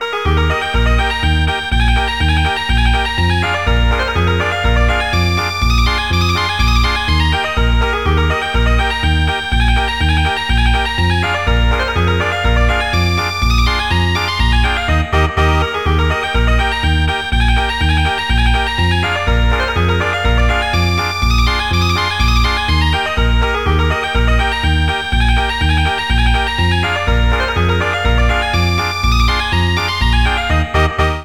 Midi
Jolly